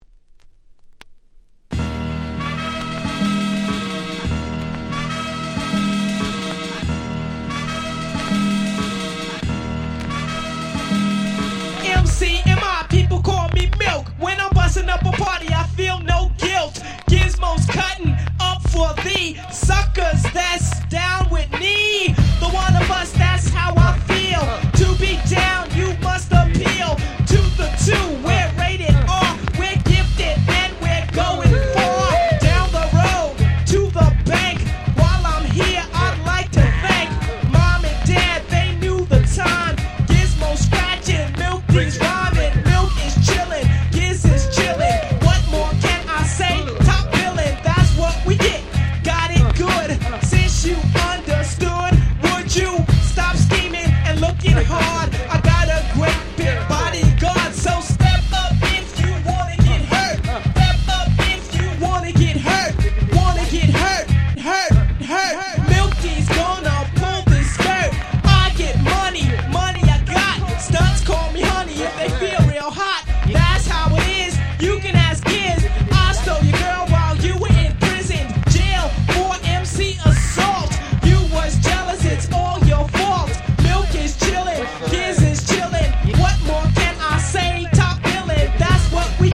DJ用にイントロにBreakがついて繋ぎ易くなってたりするアレです。